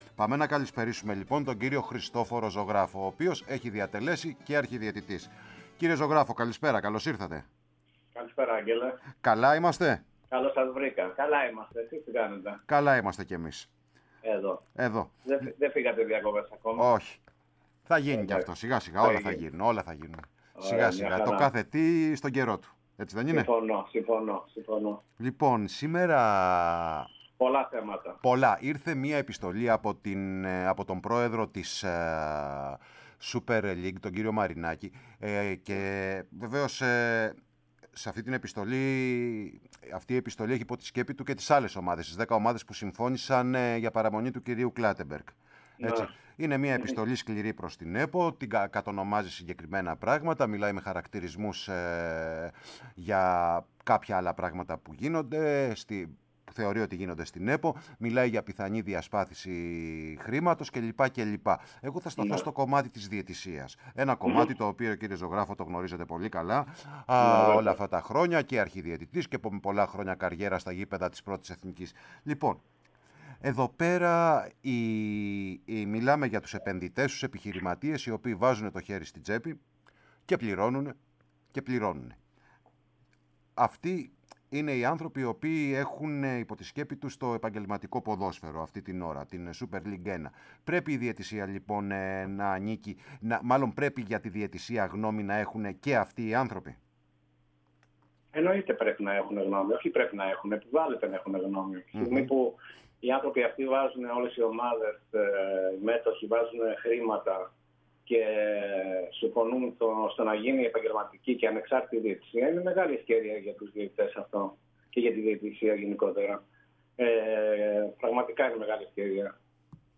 Ο 53χρονος βετεράνος διαιτητής φιλοξενήθηκε στην ΕΡΑΣΠΟΡ και μίλησε για την επαγγελματική διαιτησία, τόσο βιωματικά, όσο και βάσει πεπραγμένων.